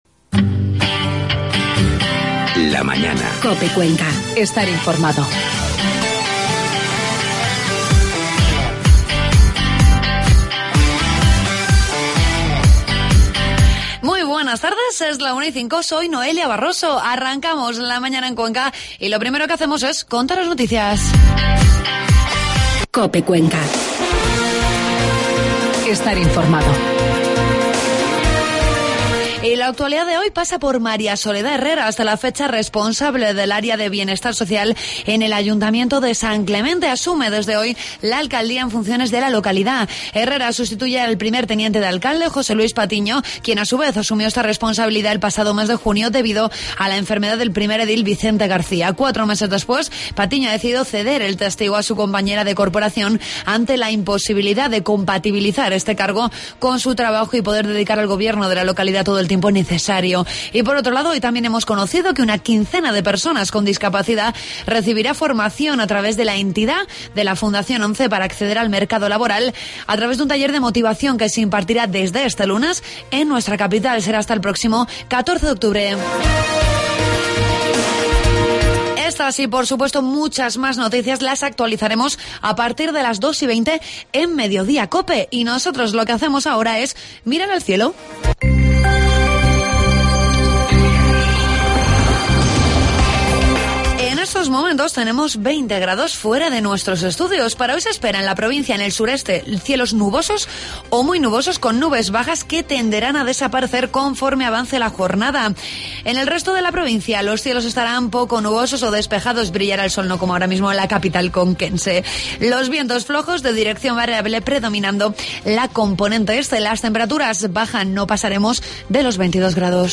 La Mañana en Cuenca, lunes 7 de octubre de 2013. Entrevistamos al alcalde de Cuenca, Juan Ávila, con el que tratamos diferentes asuntos de actualidad municipal.